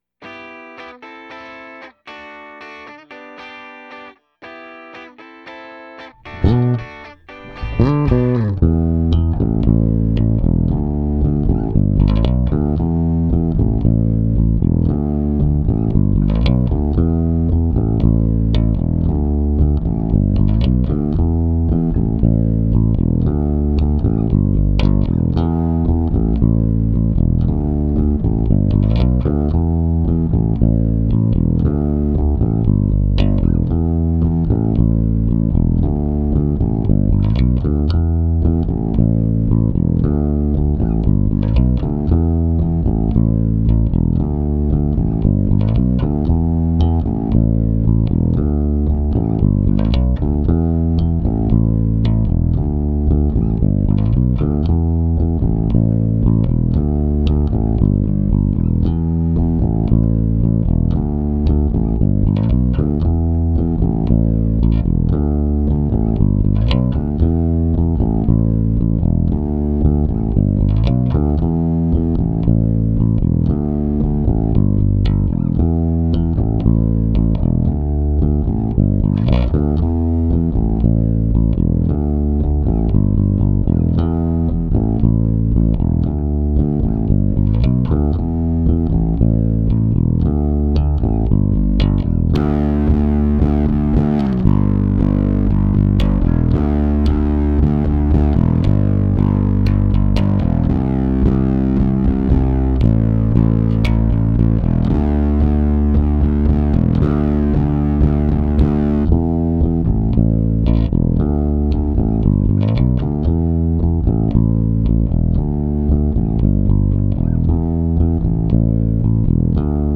(bass only)